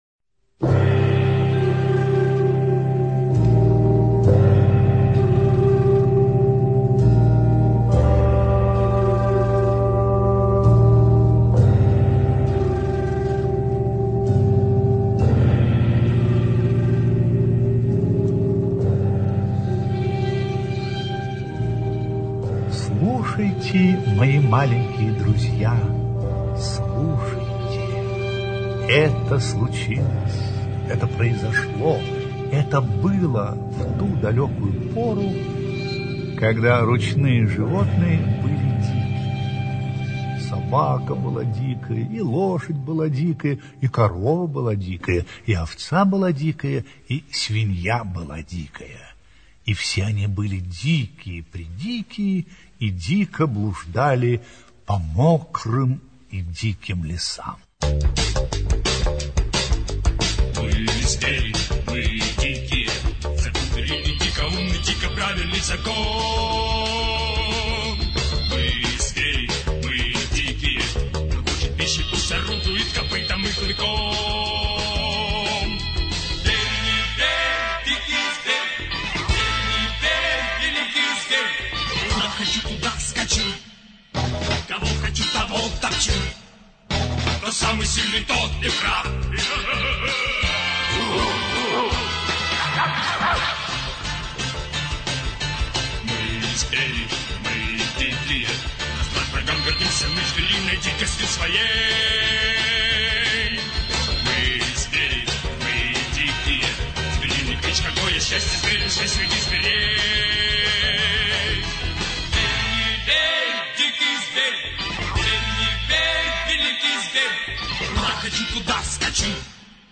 Кот, который гулял, где хотел — аудиосказку Киплинга Р. История о том, как строились взаимоотношения между человеком и свободолюбивой кошкой.